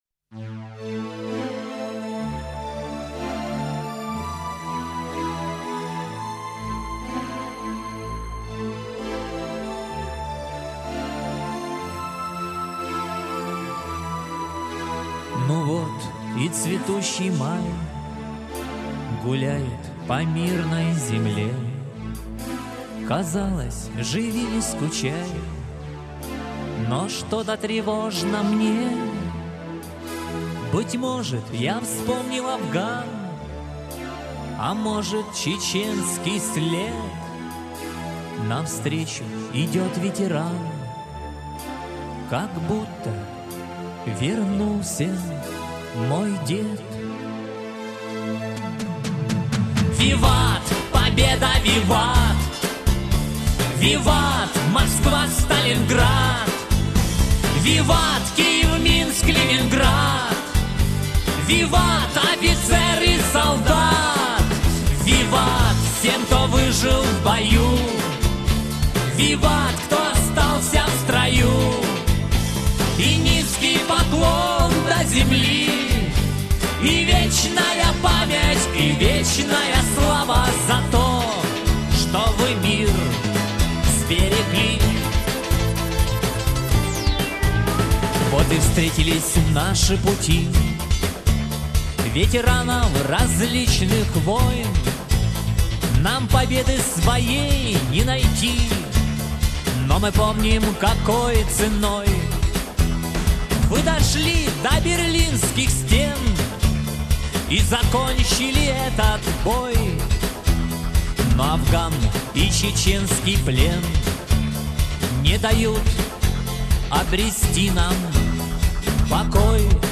Сегодня у нас состоялся праздник предстоящего дня ВДВ.